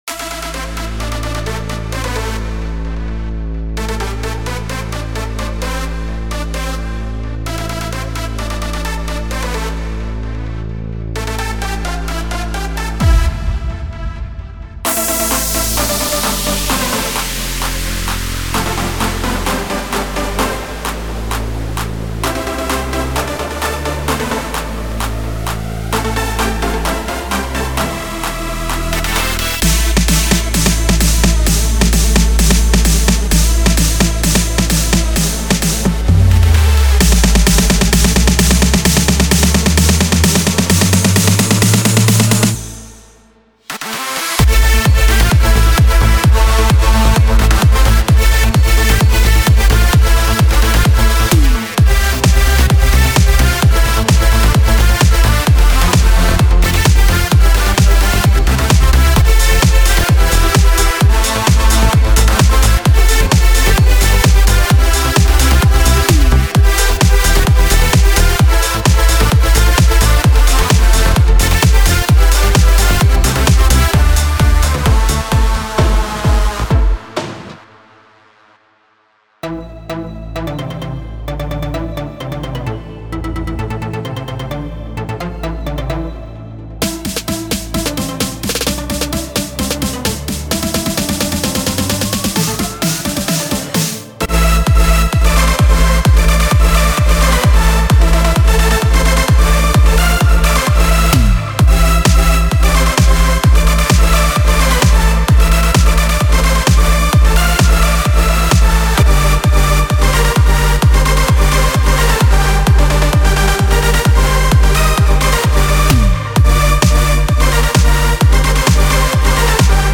מקצבים לPA 700
אתה משתמש כל הזמן באותו הדיטון הזה. ההוא עם ה-המוווון רילס… זה טיפה חופר - אבל מאד ממלא.
ובדרופים הגבוהים דחפת איתו כל פעם גם איזה צליל קטנטן כזה נקודתי על הסולו שמאד מציק ולא קשור. ודבר אחרון - התלהבת יותר מידי על הסאונדים החשמליים האלה…